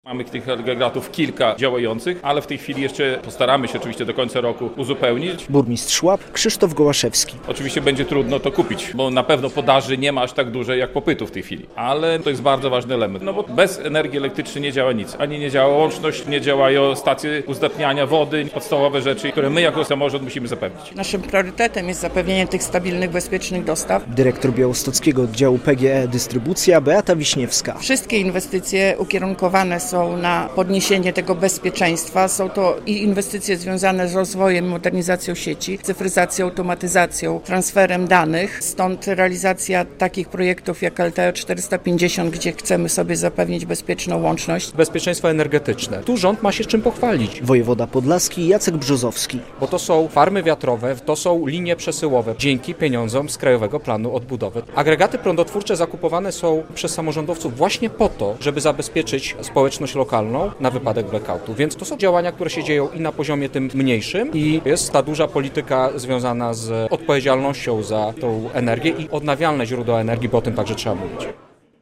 Dyskutują o nowych inwestycjach, energii odnawialnej i przede wszystkim o bezpieczeństwie energetycznym. Podlascy samorządowcy i przedstawiciele PGE Dystrybucja biorą udział w VII Forum Energetycznym dla Samorządów, które odbywa się w Białymstoku.